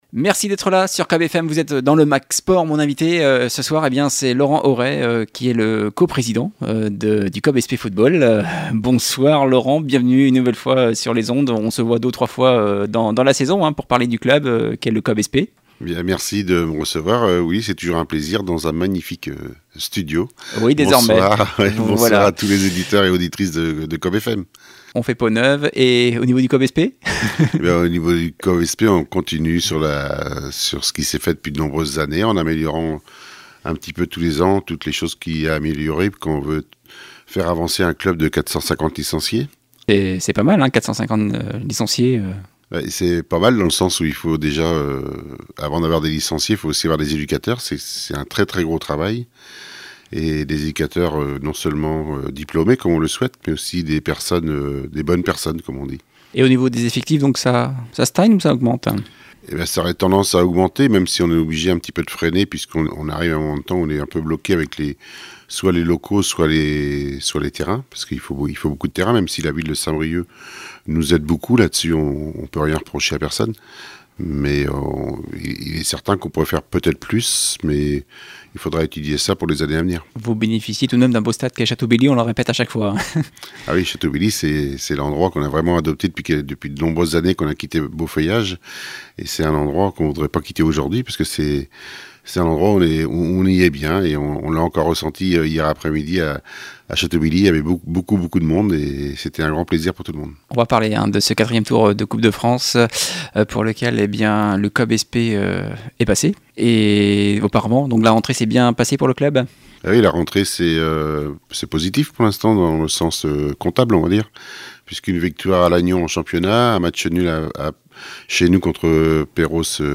Invité du Mag Sport hier soir